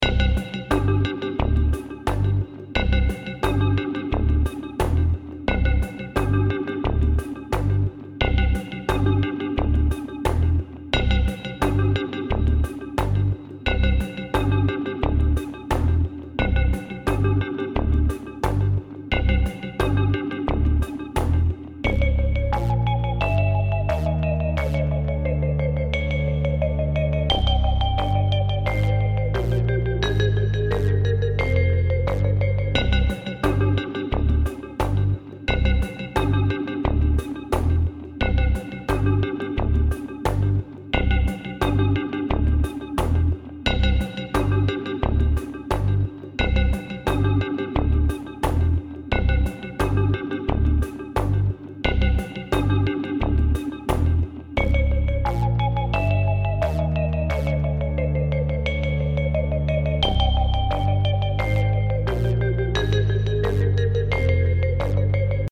ゲームにあるような暗い洞窟をイメージした単純なループ楽曲。